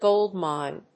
発音記号・読み方
/ˈgoˌldmaɪn(米国英語), ˈgəʊˌldmaɪn(英国英語)/
アクセントgóld mìne